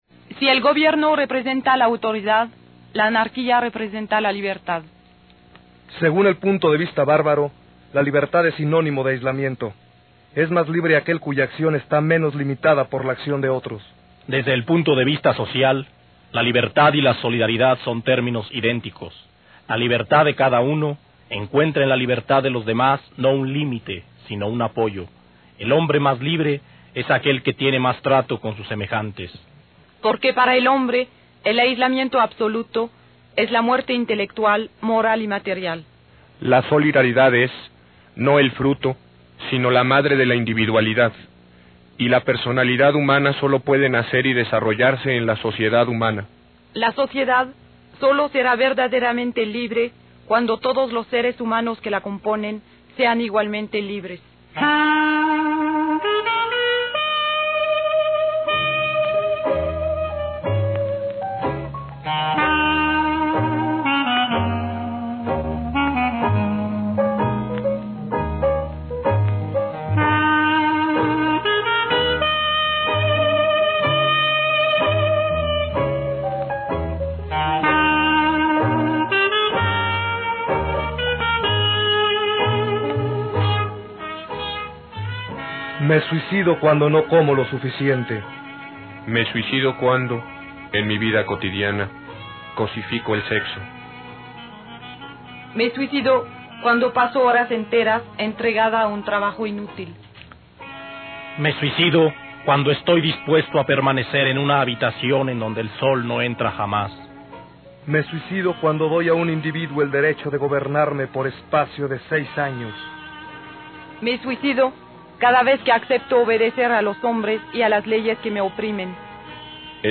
Su estructuraci�n, la elaboraci�n del gui�n, los necesarios ensayos y la selecci�n de las canciones que incluimos, al igual que del fondeo musical, nos llev�, aproximadamente, cinco meses.